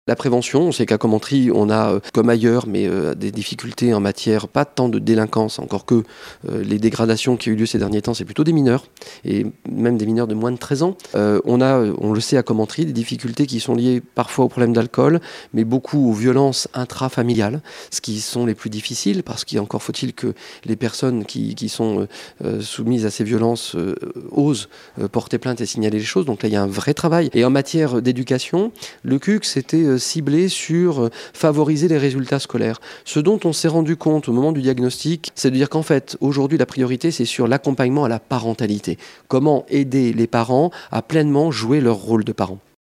Le maire de Commentry. Claude Riboulet revient sur les priorités de ce contrat de ville